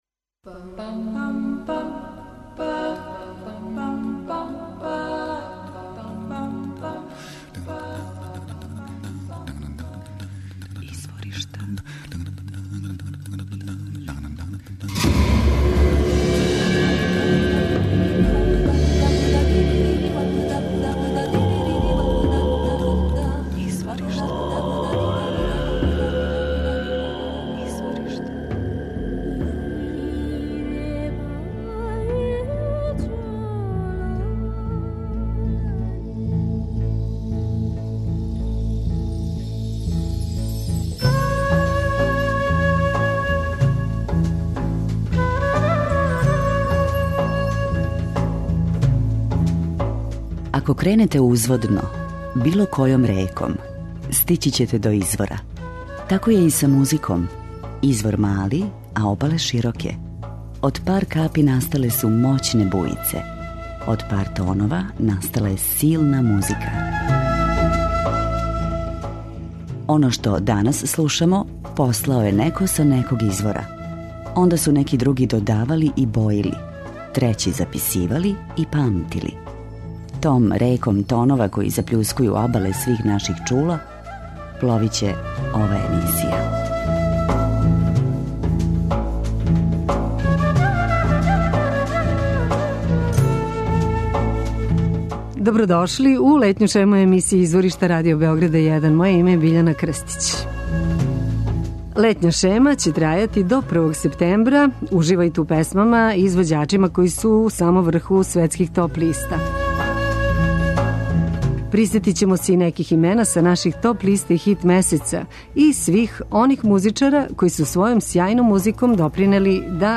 Дружићемо се са музичарима чију музику дефинишу у оквирима world music.